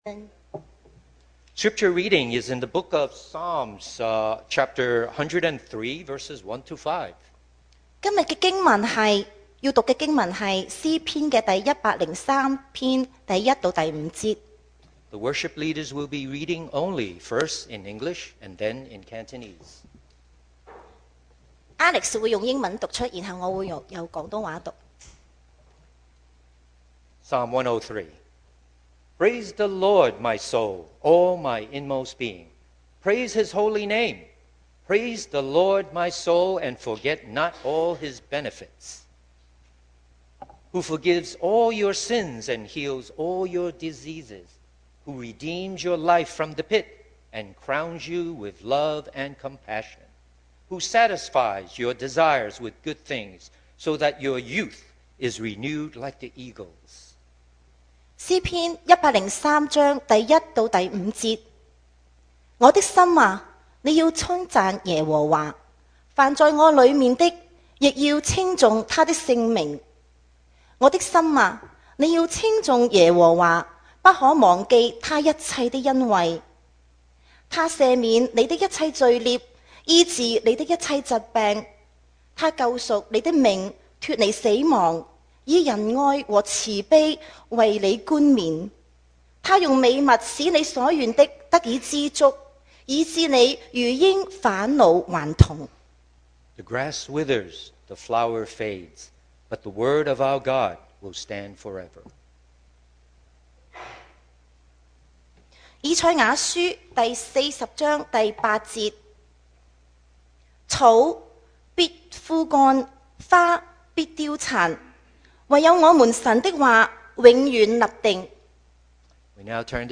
Service Type: Thanksgiving Day